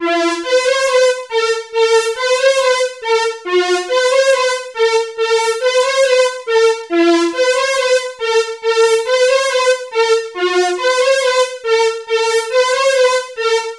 Exodus - Synth Strings.wav